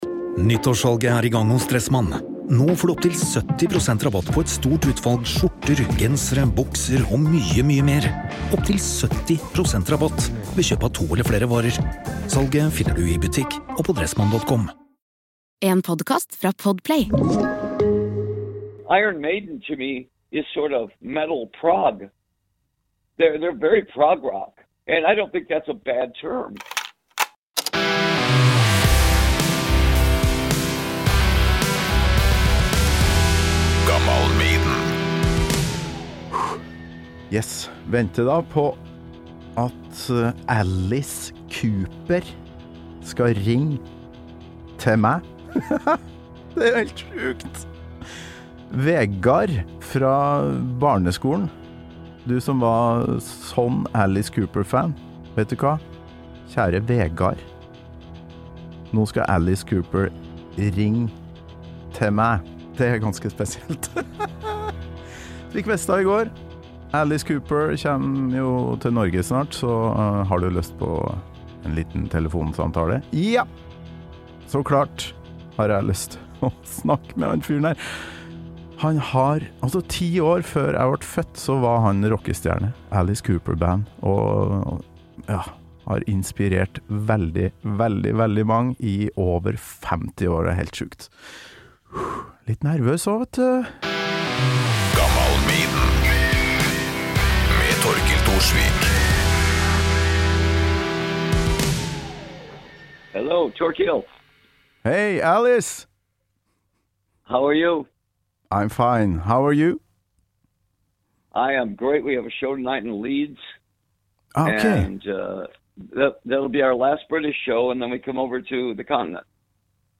Beklager litt laber telefonlyd, men det får farsken meg bare være.